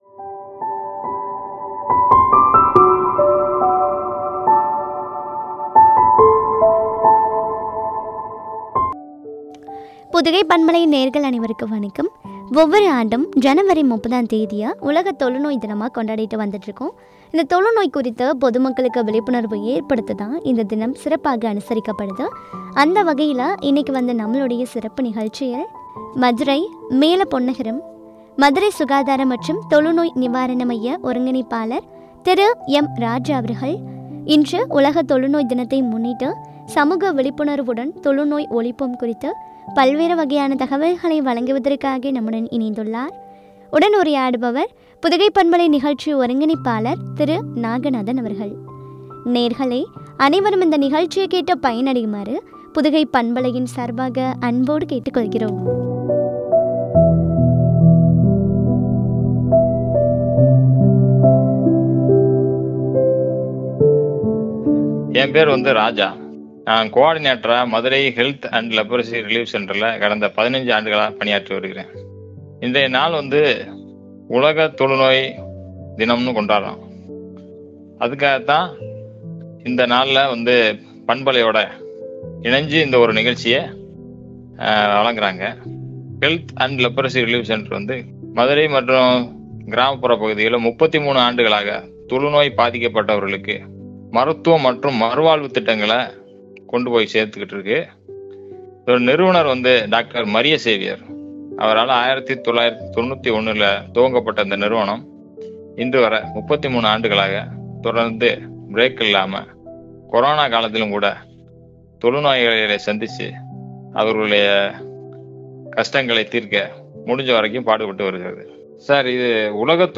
குறித்த வழங்கிய உரையாடல்.